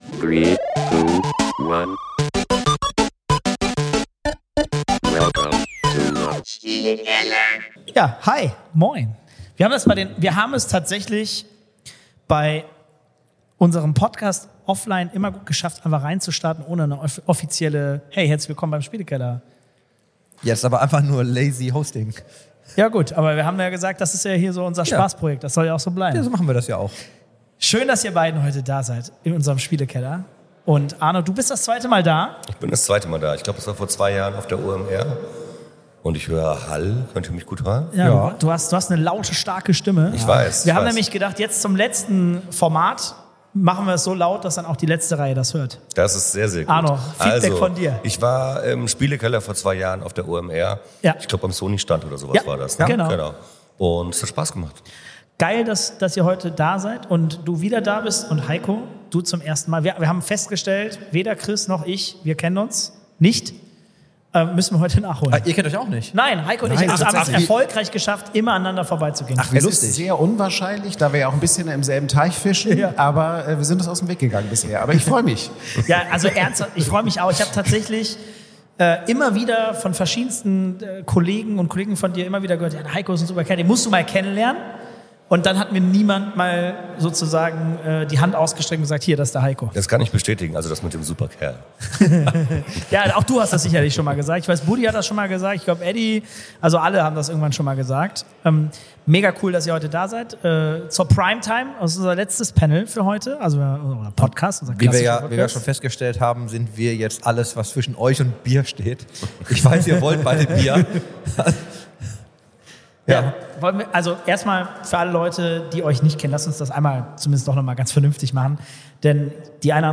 Beschreibung vor 1 Jahr Spielekeller-Folge #144 ist ein Live-Podcast vom B2B Opening des XPERION Hamburg x The Circle Event.